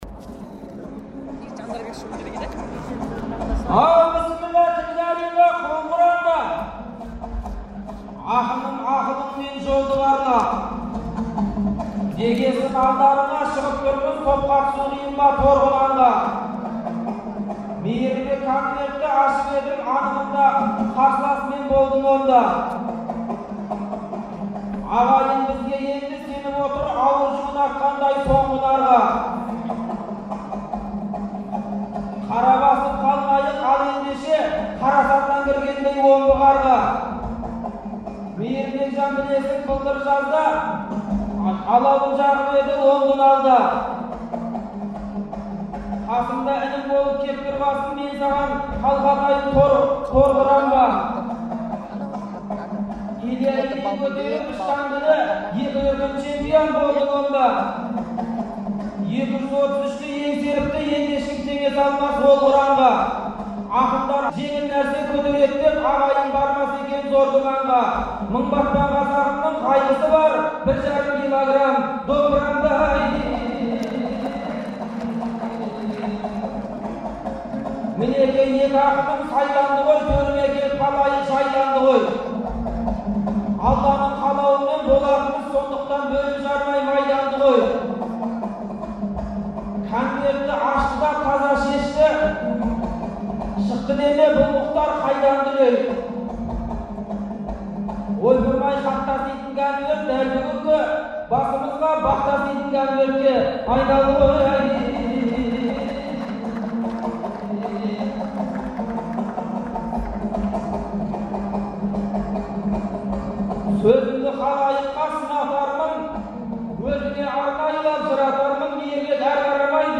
Астанада өткен айтыстың ақтық айналымында